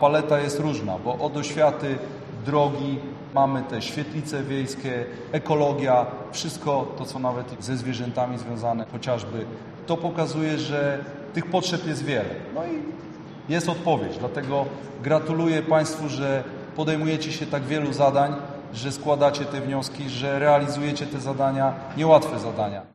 O realizowanych projektach mówił podczas spotkania z beneficjentami, Marcin Grabowski, radny województwa mazowieckiego: